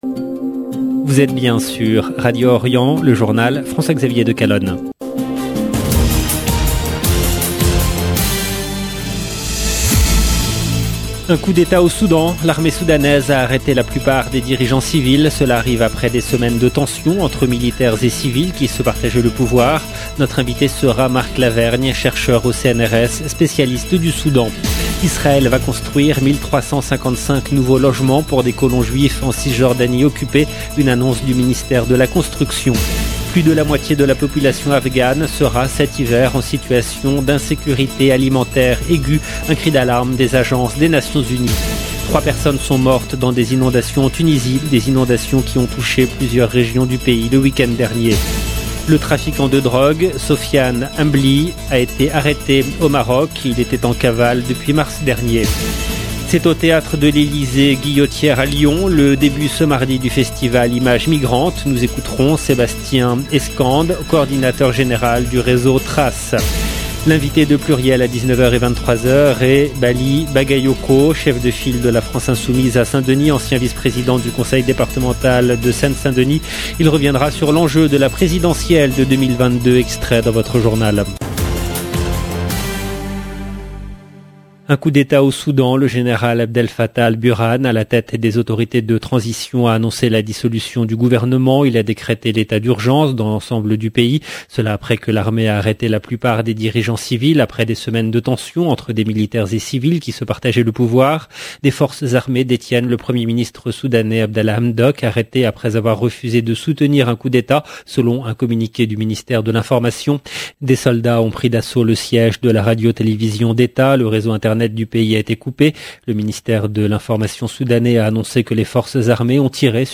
EDITION DU JOURNAL DU SOIR EN LANGUE FRANCAISE DU 25/10/2021